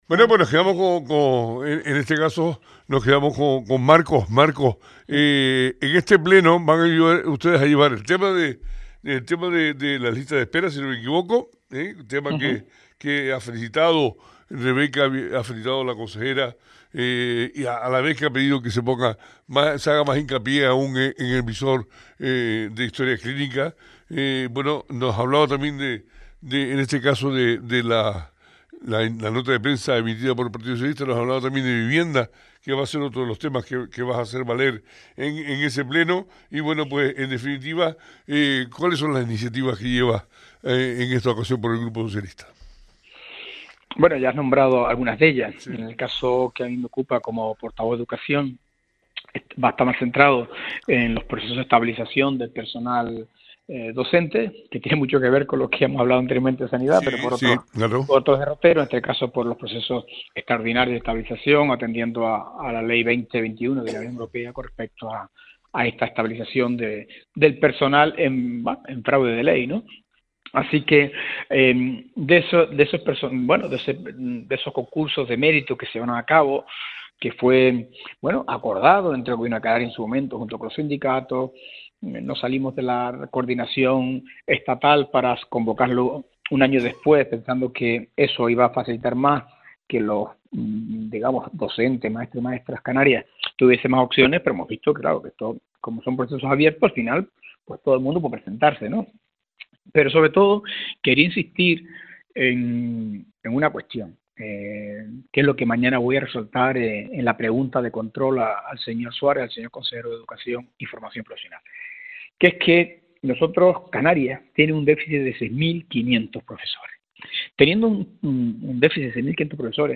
En Parlamento entrevistamos a Marcos Hernández, portavoz en Educación del Partido Socialista Canario.